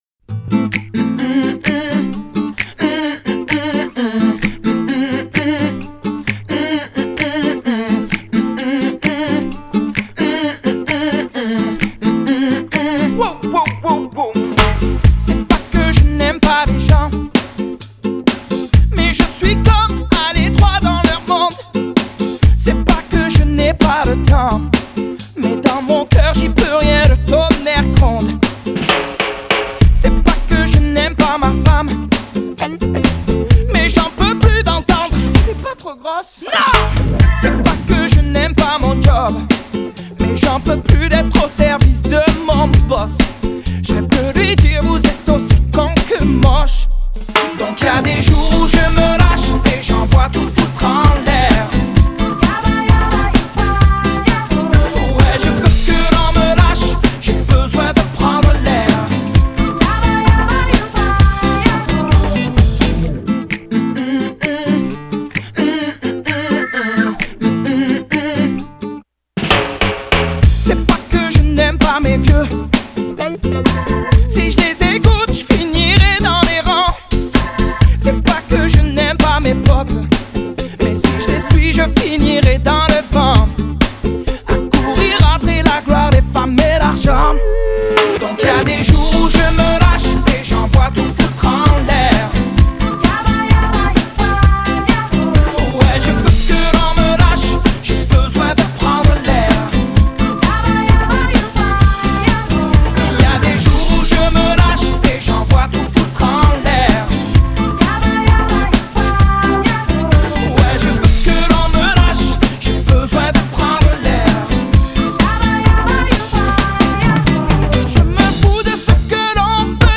chanson française